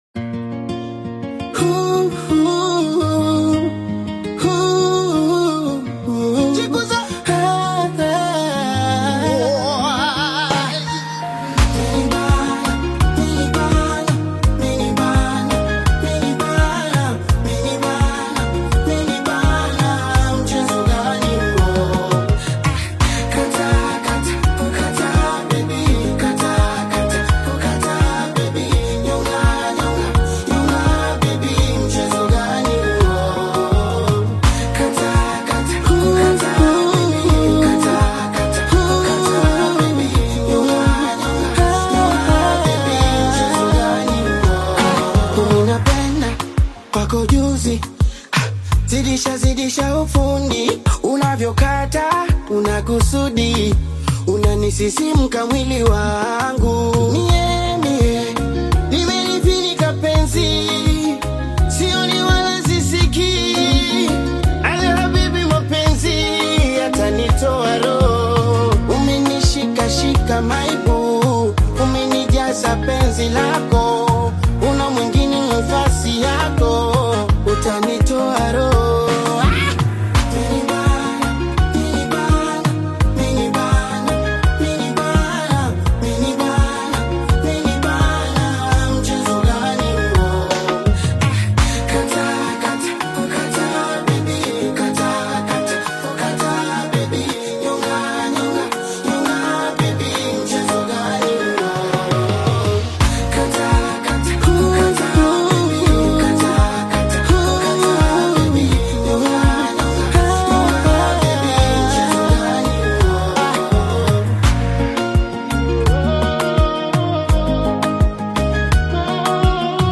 AfrobeatAudioKenyan Music